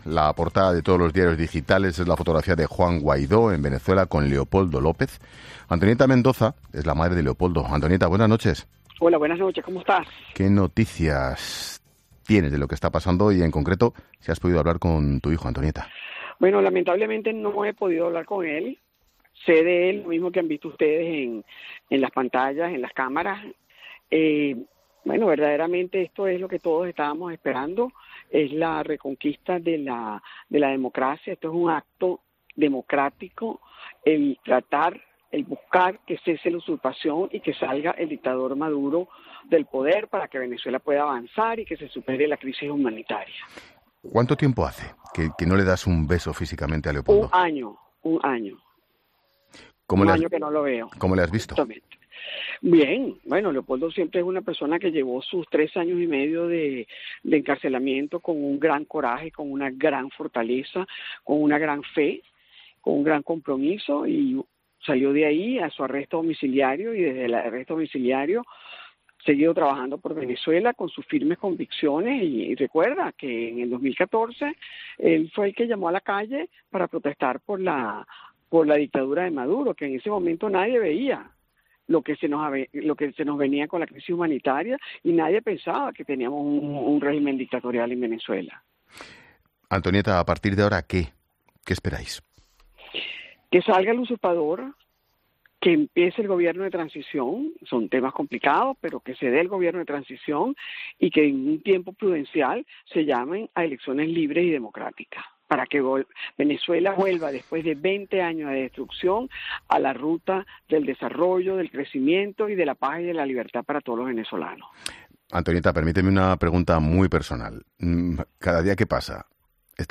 La madre del opositor venezolano asegura que está "muy orgullosa" y espera que las movilizaciones acaben con el "usurpador Maduro" y en un proceso electoral. Esucha la entrevista completa aquí